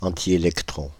Ääntäminen
Synonyymit positron Ääntäminen France (Île-de-France): IPA: [ɑ̃.ti.e.lik.tʁɔn] Tuntematon aksentti: IPA: /ɑ̃.ti.e.lɛk.tʁɔ̃/ Haettu sana löytyi näillä lähdekielillä: ranska Käännöksiä ei löytynyt valitulle kohdekielelle.